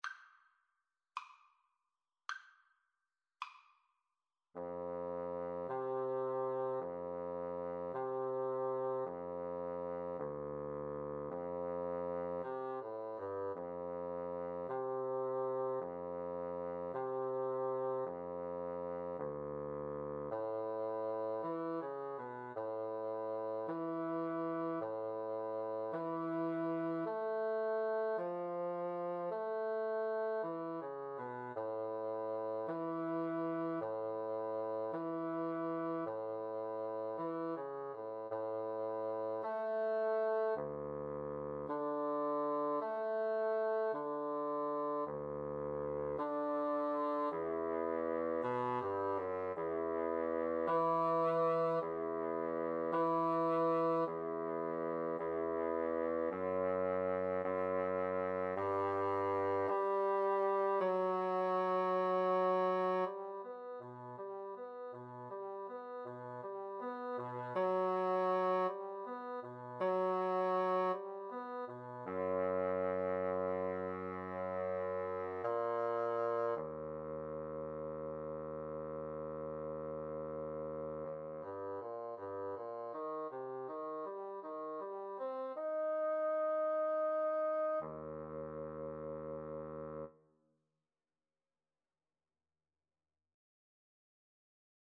Andante
6/8 (View more 6/8 Music)